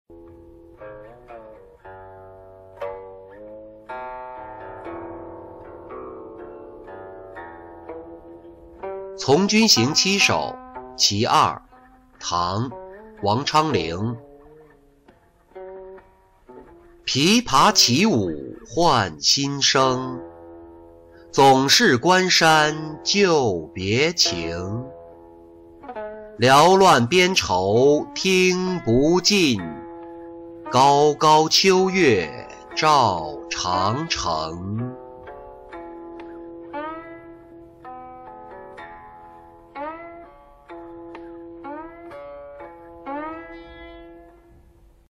从军行七首·其二-音频朗读